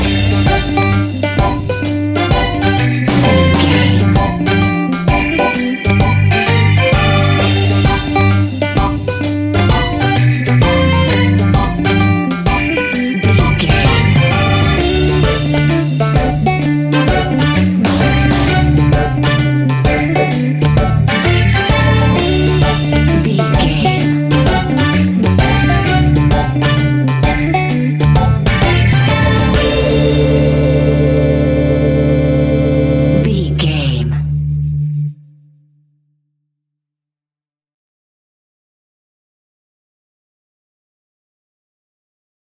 Ionian/Major
D
laid back
chilled
off beat
drums
skank guitar
hammond organ
transistor guitar
percussion
horns